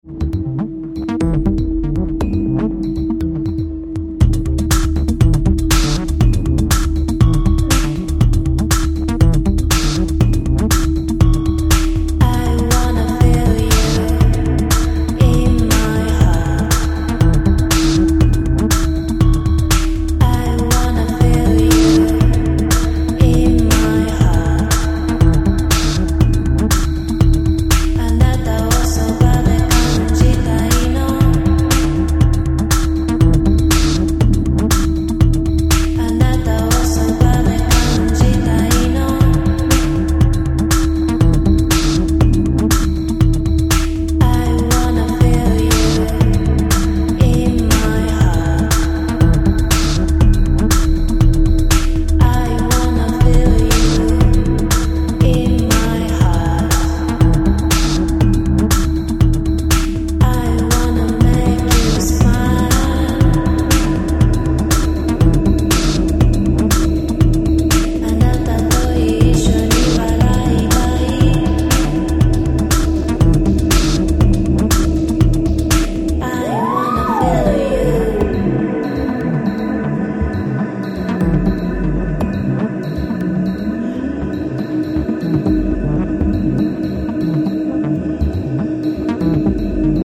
ニューウェイヴ/EBM/アシッド・ハウス/トライバル等の要素を退廃的に昇華した、濃厚なデビュー作となっています。